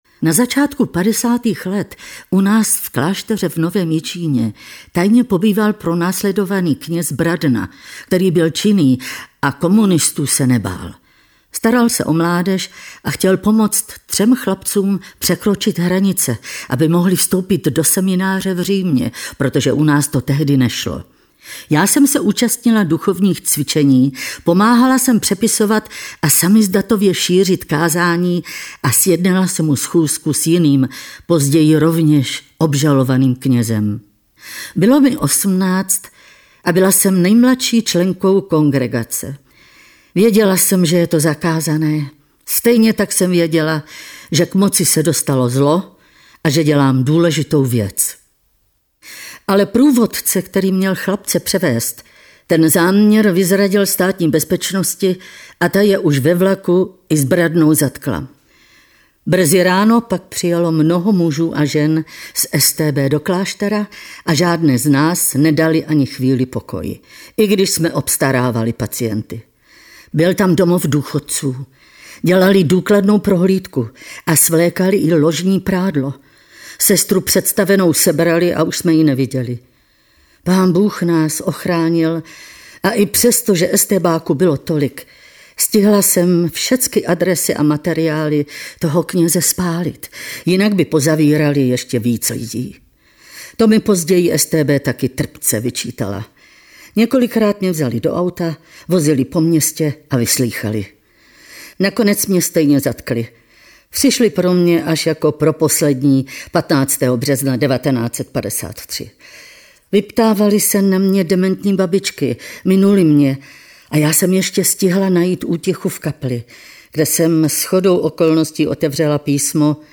• Hudba Arvo Pärt
její dílo bylo adaptováno pro rozhlas jako četba na pokračování a získalo také svou divadelní podobu (Divadlo Jonáša Záborského Prešov, Národní divadlo moravskoslezské Ostrava).